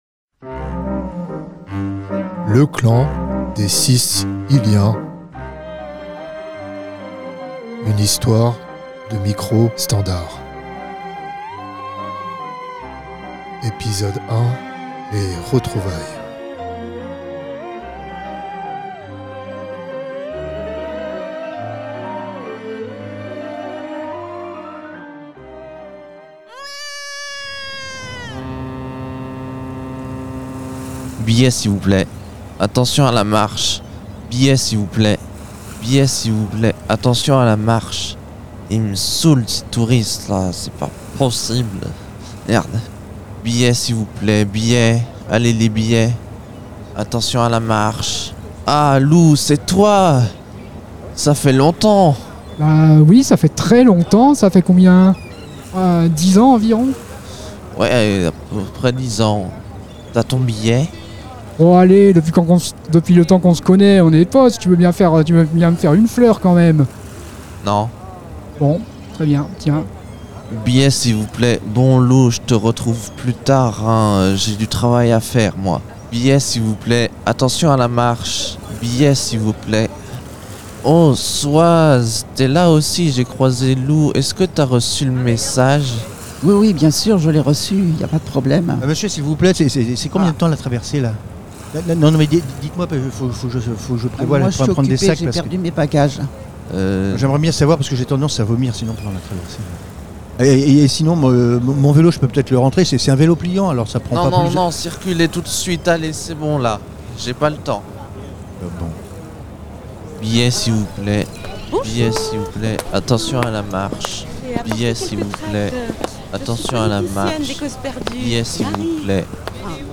la fiction sonore mystérieuse!